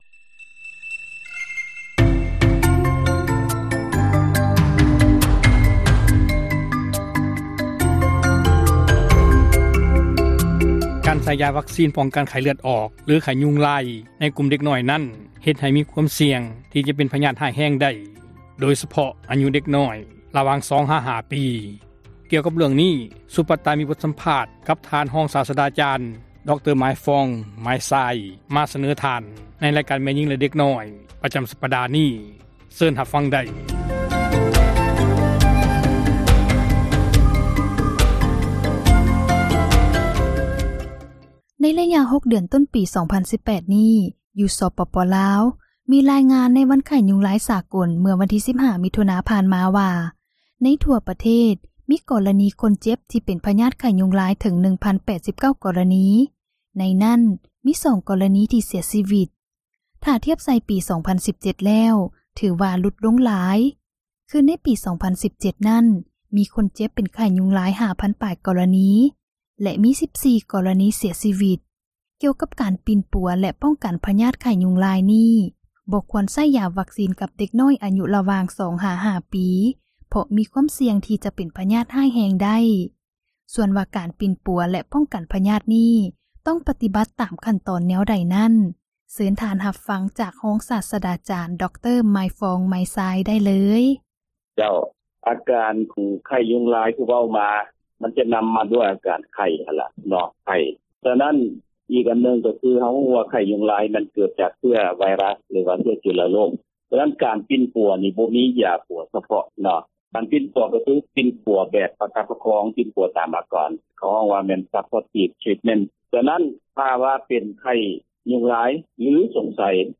ສຳພາດ ຮອງສາສດາຈານ ດຣ.